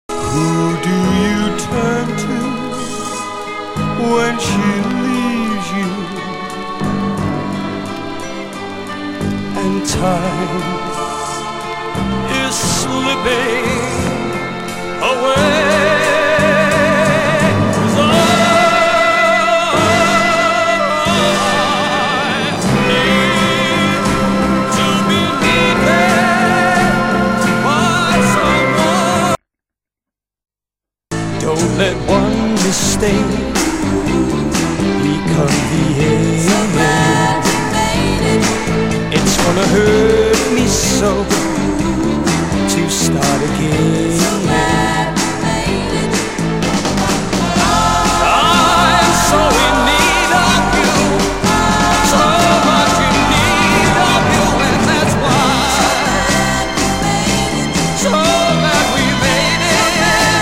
両面共に氏の朗々と歌い上げる歌唱スタイルを上手く活かした作風だったが売れなかった。
(税込￥19800)   UK SOUL / POP